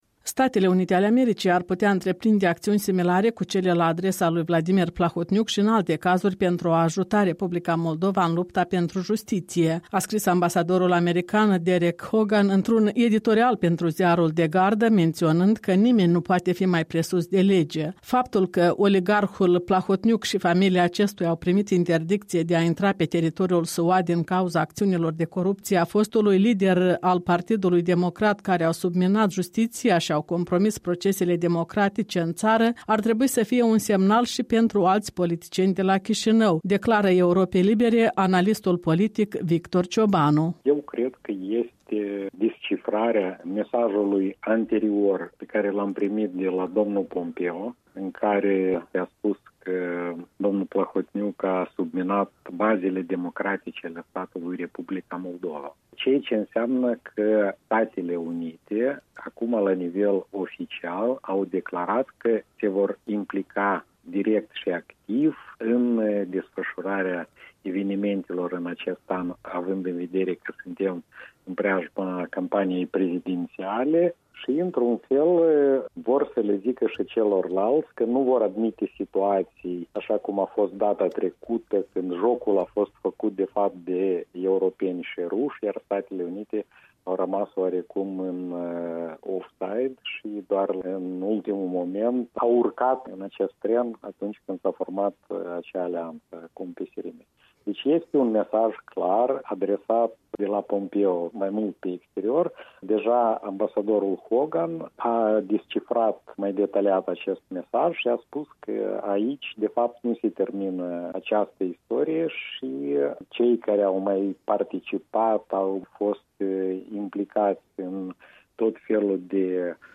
Retrospectiva săptămânii alături de analistul politic